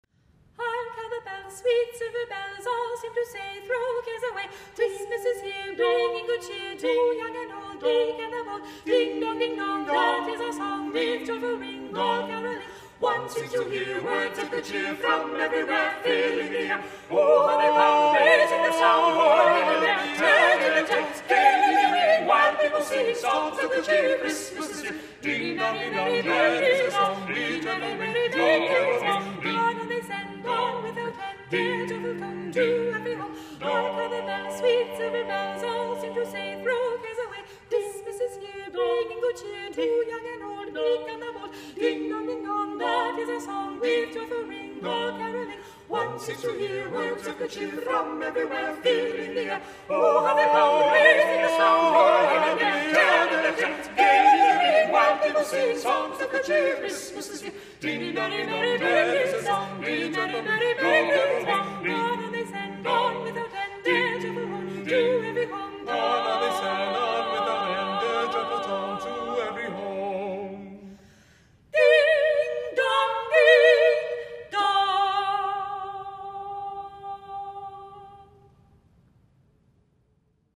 This holiday season why not spoil your party guests, employees or customers with a quartet of professional singers performing Holiday classics?
carolers_carol_of_bells.mp3